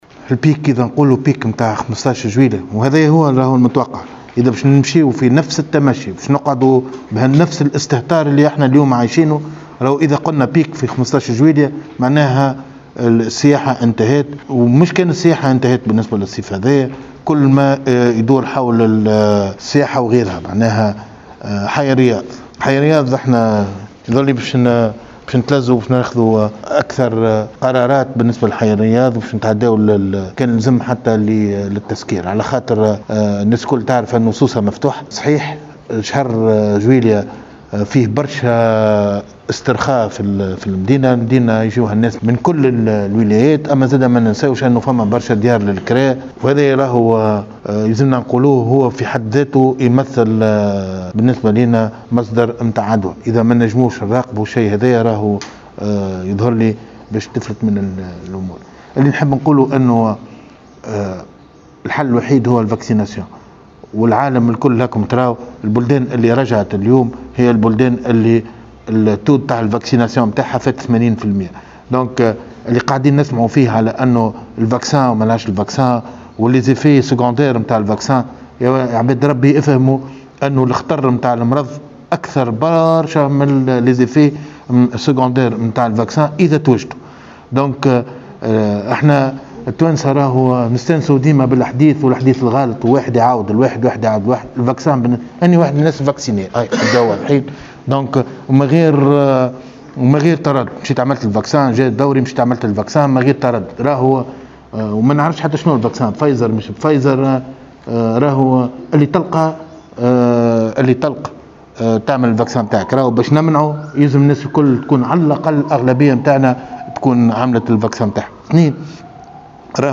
وبيّن خالد خلال ندوة صحفية عُقدت بمقر ولاية سوسة، أن الحلّ الوحيد يبقى التلقيح، وهو شخصيا تلقى التطعيم حين حان دوره، دون علمه بنوعه، تجنبا للتشكيك والجدل الذي أثير لدى المواطنين حول نجاعة وجدوى التلقيح، وإيمانا منه بأنّ جميع التلاقيح فعّالة ضدّ الكوفيد-19.